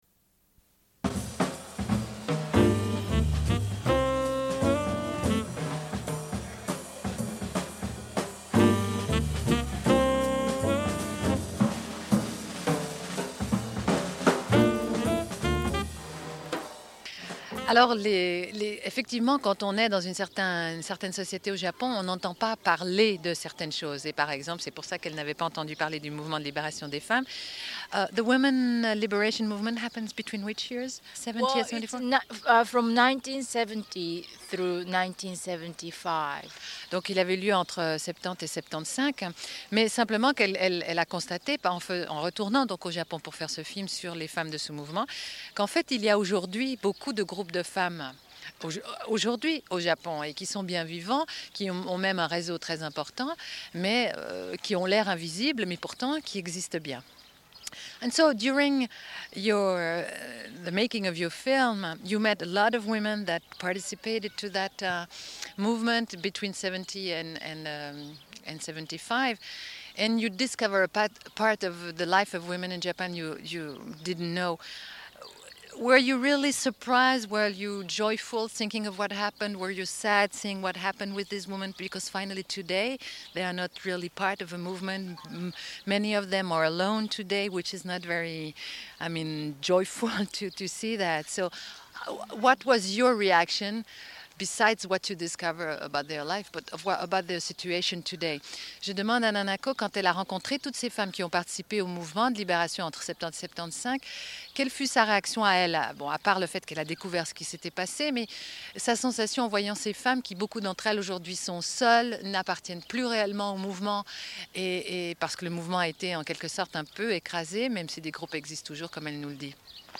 Une cassette audio, face A31:05
Sommaire de l'émission : autour du Festival International de films de femmes de Créteil. Diffusion d'enregistrements et d'entretiens réalisés sur place.